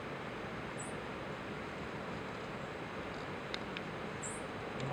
Cerquero Amarillo (Atlapetes citrinellus)
Nombre en inglés: Yellow-striped Brushfinch
Condición: Silvestre
Certeza: Fotografiada, Vocalización Grabada